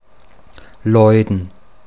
Deutsch-mundartliche Form
[lɔidn]
Lajen_Mundart.mp3